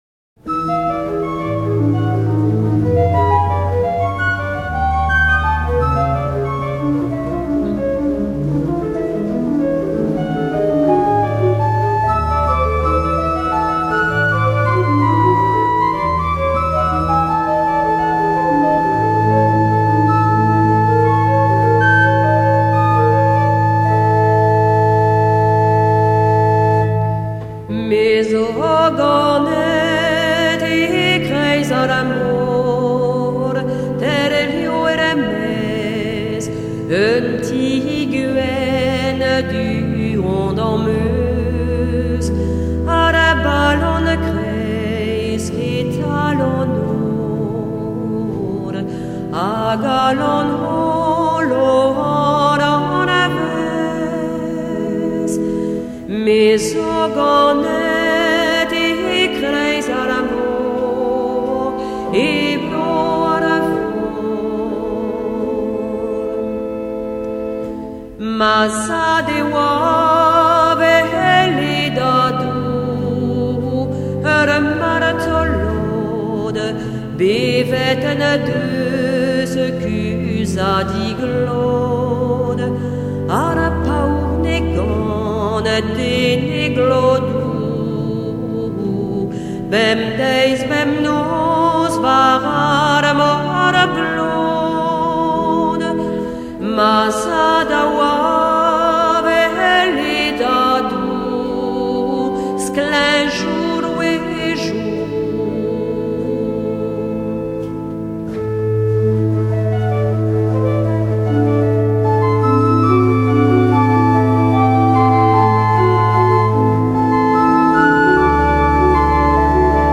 一首电子音乐 非常有气势 富有浓郁的神秘感 让人联想到古老的爱尔兰岛 仙气四溢